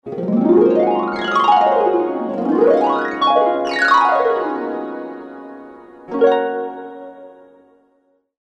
Звуки арфы
Звук от движения руки по струнам арфы